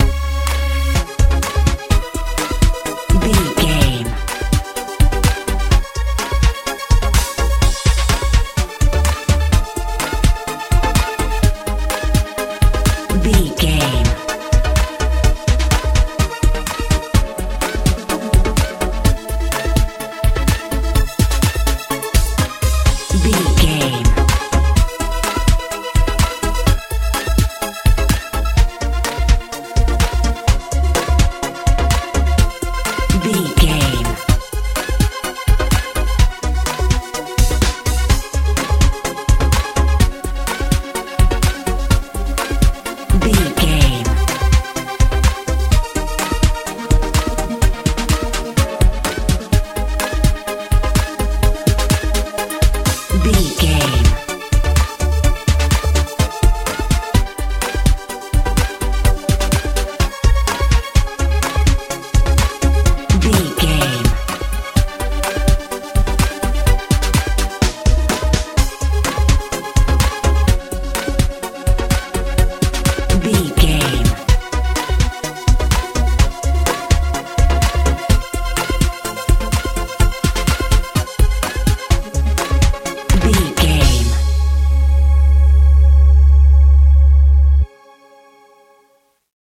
modern dance feel
Ionian/Major
C♯
magical
mystical
bass guitar
drums
synthesiser
80s
90s
strange
suspense